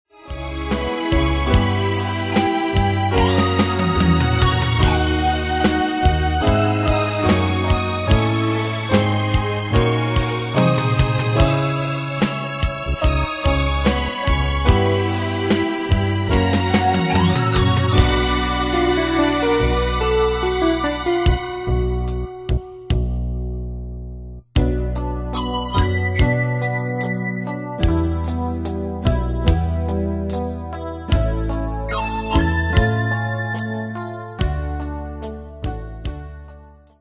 Style: PopFranco Année/Year: 1976 Tempo: 73 Durée/Time: 4.38
Danse/Dance: Ballade Cat Id.
Pro Backing Tracks